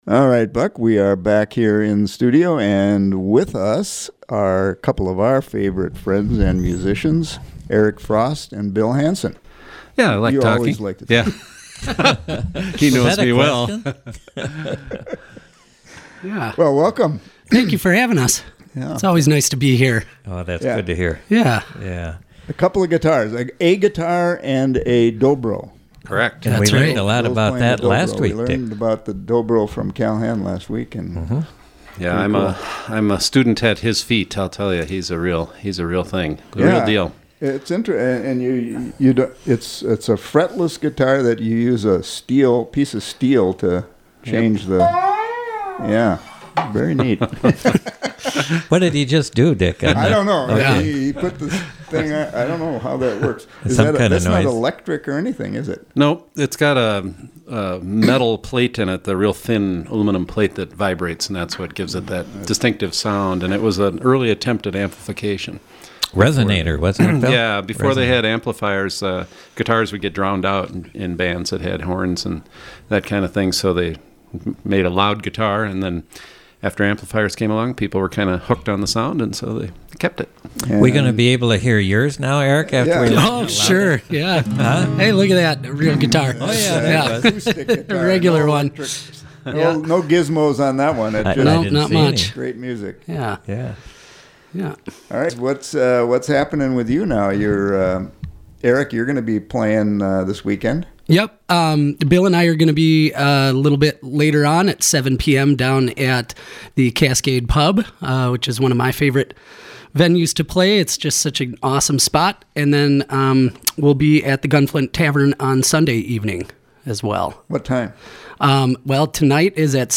Studio A on a snowy February night. They played a mix of original music and covers and, of course, there was lots of talk and laughter. Program: Live Music Archive The Roadhouse